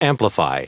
Prononciation du mot amplify en anglais (fichier audio)
Prononciation du mot : amplify